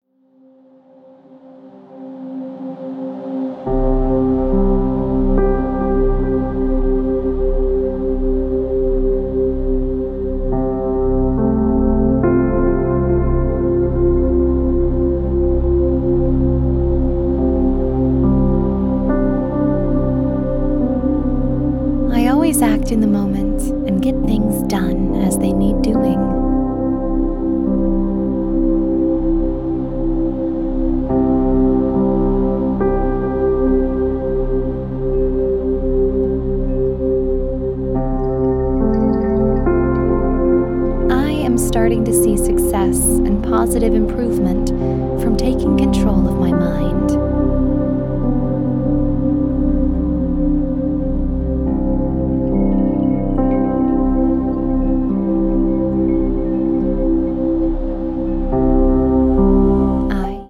Track two (1 hour)Tap Into Your Personal Power Affirmation AUDIBLE VERSION (30 Audible Spoken Affirmations)